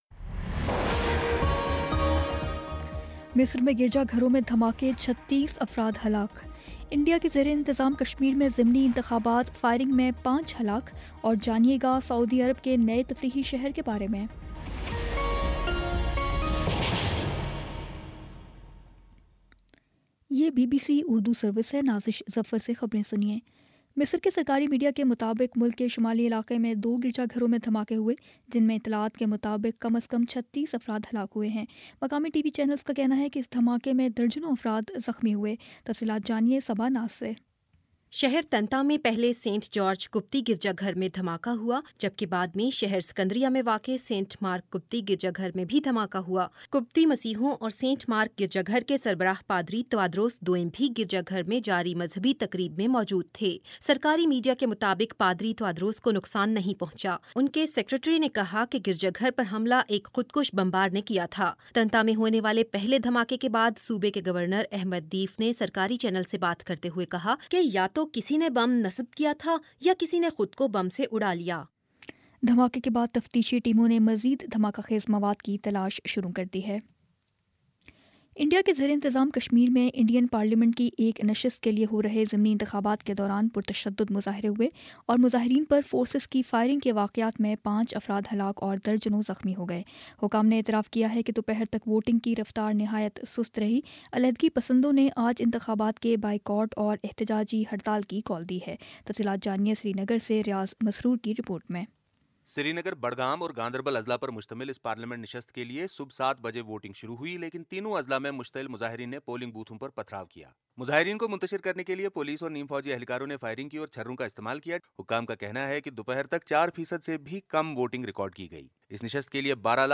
اپریل 09 : شام سات بجے کا نیوز بُلیٹن